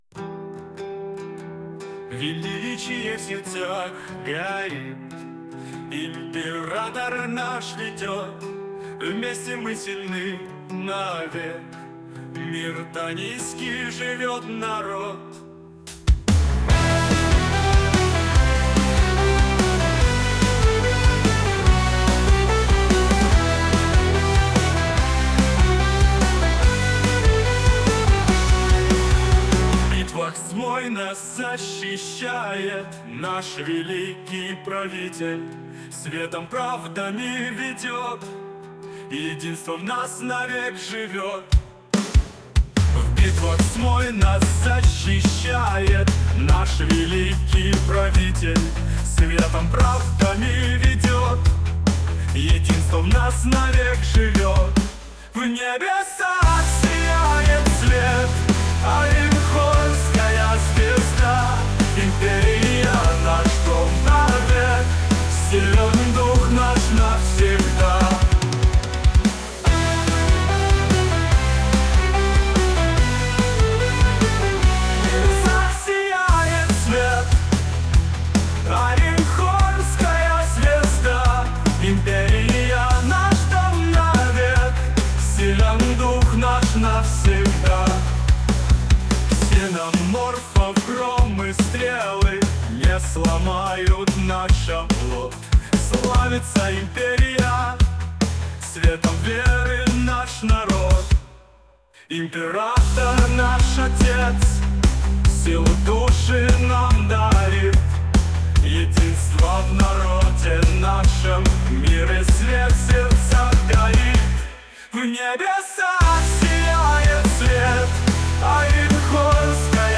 Патриотическая песня, написанная выдающимся деятелем культуры Хиджеральдом Гигосом.